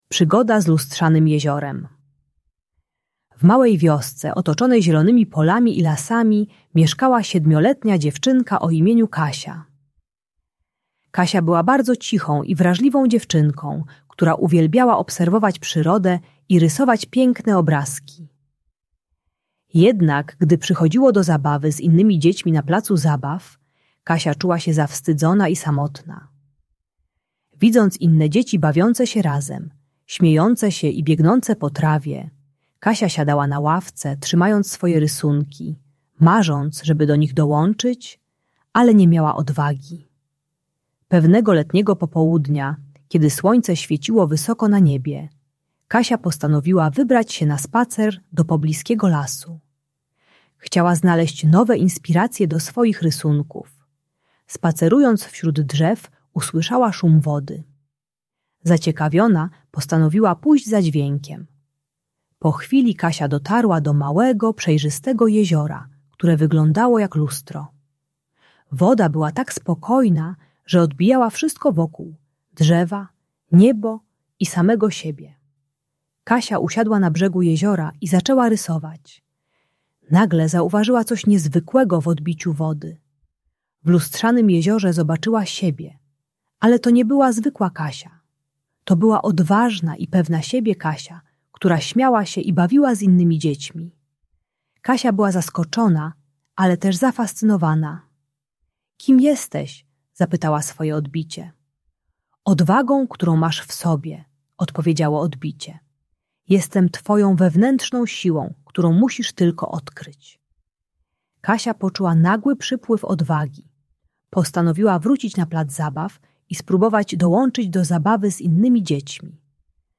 Przygoda z Lustrzanym Jeziorem - Lęk wycofanie | Audiobajka